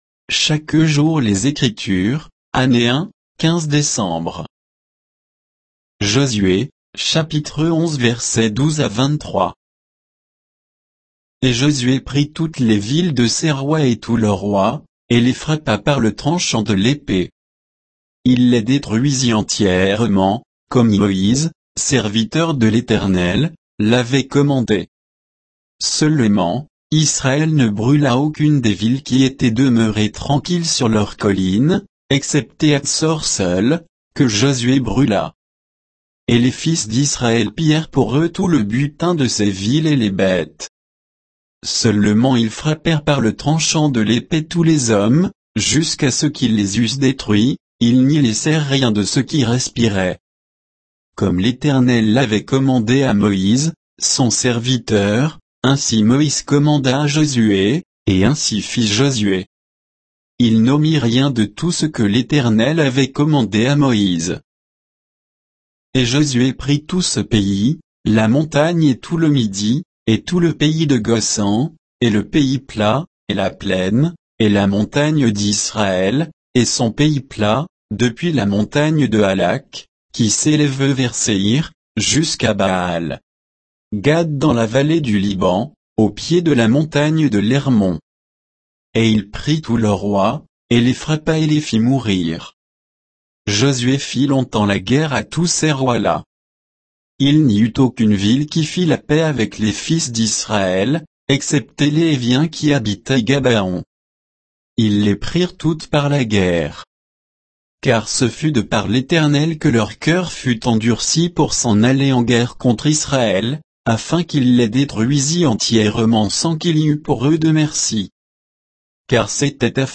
Méditation quoditienne de Chaque jour les Écritures sur Josué 11